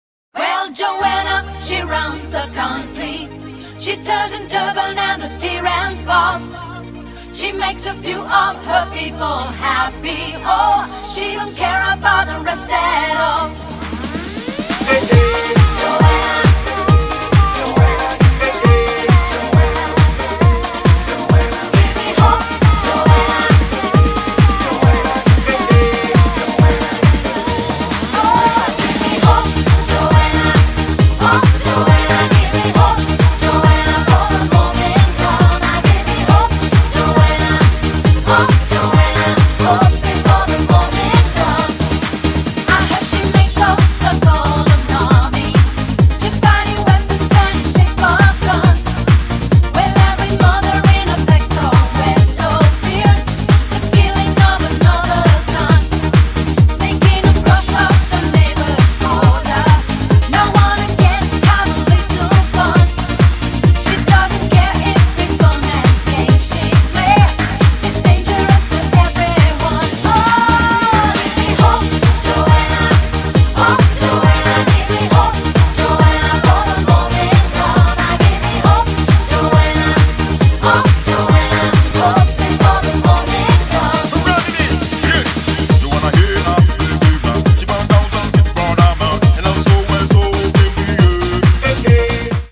BPM136--1
Audio QualityMusic Cut
- Music from custom cut